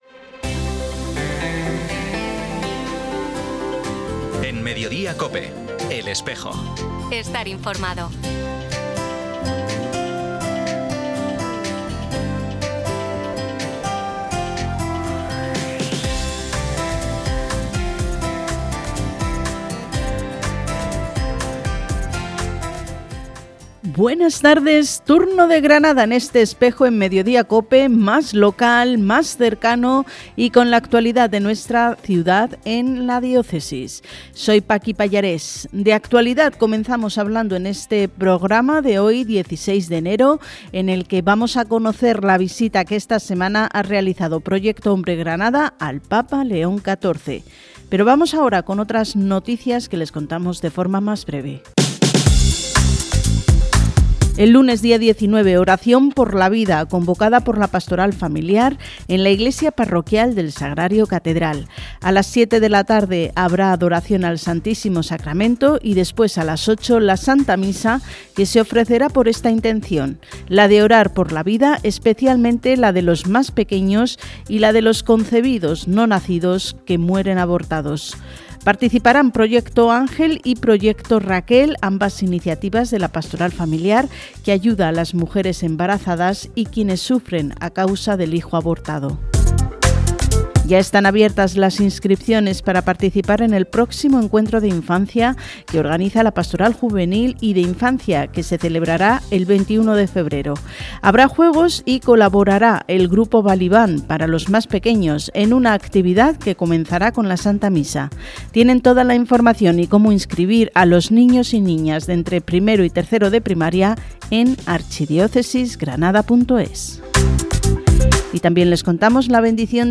Programa emitido hoy viernes, 16 de enero, en COPE Granada y COPE Motril.